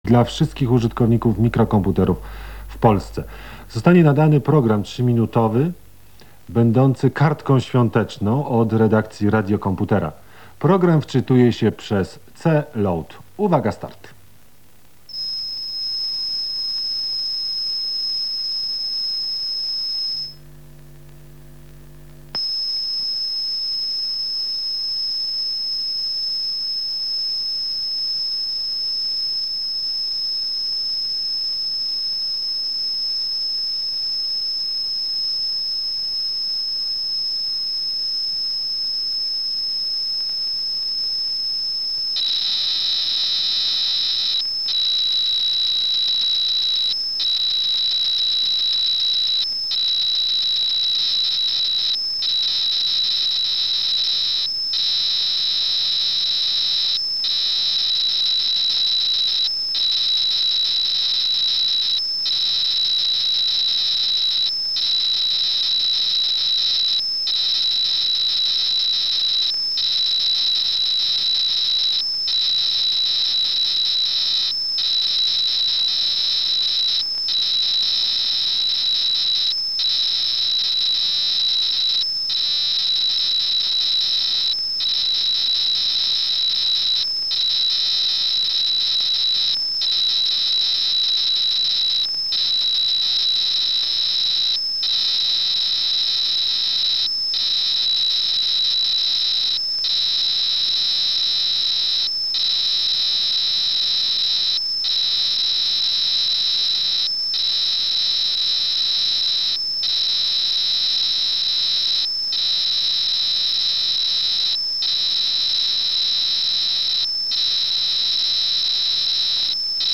Nagarania mają jakby przydźwięk sieciowy i nie da się tego pozbyć bez utraty samego sygnału.